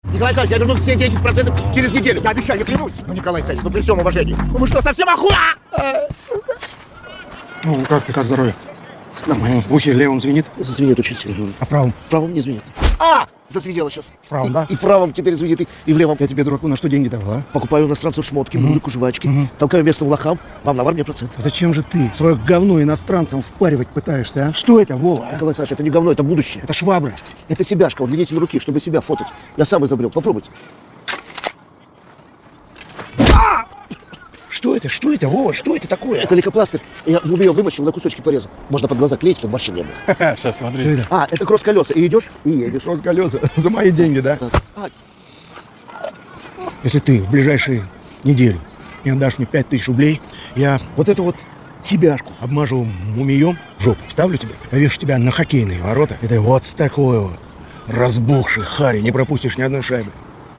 из сериалов
диалог
удары
драка
угрожающие